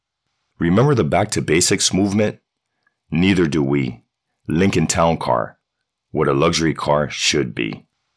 From warm to authoritative, I bring the right tone every time—tailored to connect with your audience.
Commercial Automotive (short)